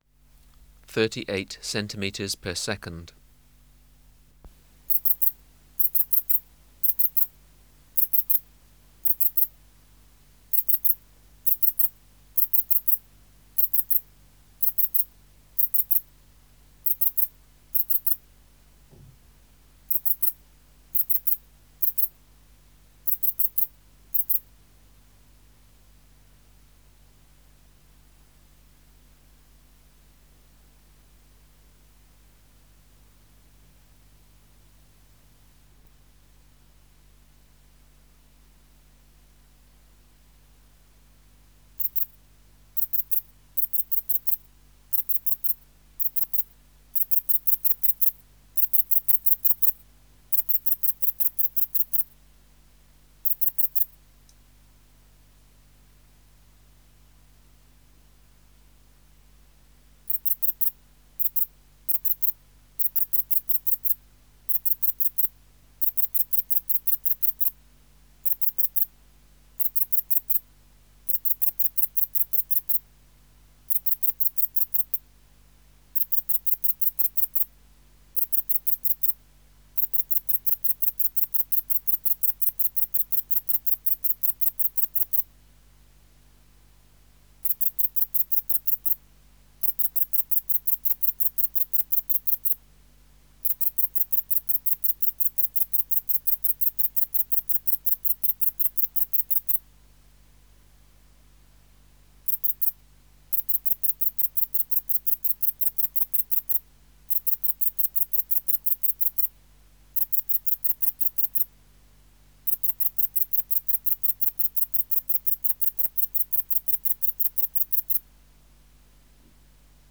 Recording Location: BMNH Acoustic Laboratory
Reference Signal: 1 kHz for 10 s
Substrate/Cage: Large recording cage
Microphone & Power Supply: Sennheiser MKH 405 Distance from Subject (cm): 35 Filter: Low Pass, 24 dB per octave, corner frequency 20 kHZ